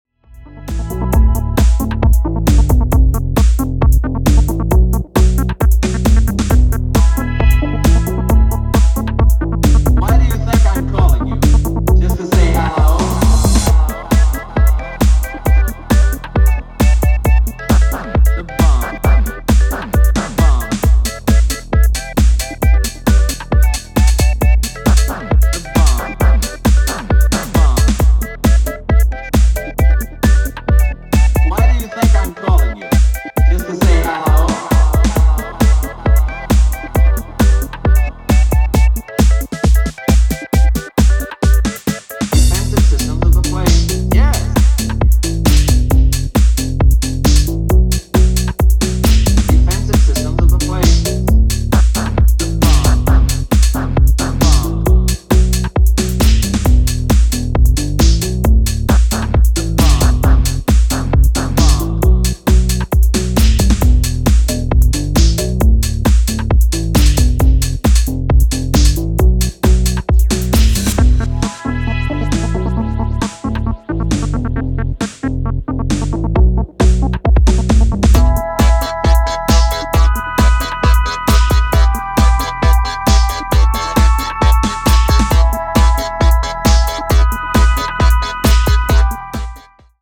Wavey leftfield minimal grooves
Minimal , Tech house